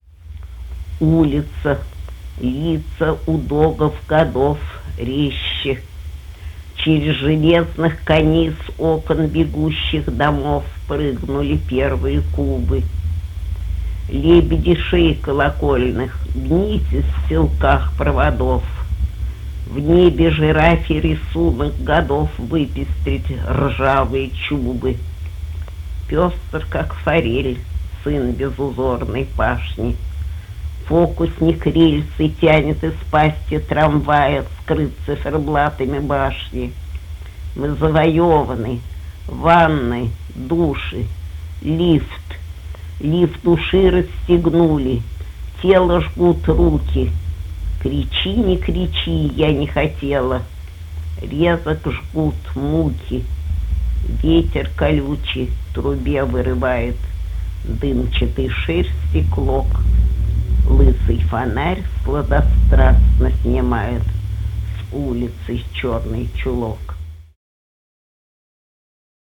12. «Лили Брик читает Маяковского – Из улицы в улицу» /
Brik-chitaet-Mayakovskogo-Iz-ulicy-v-ulicu-stih-club-ru.mp3